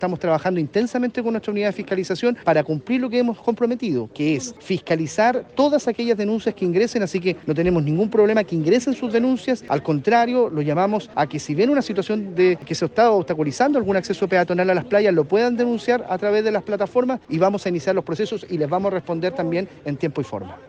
cuna-2-seremi-bienes-nacionales.mp3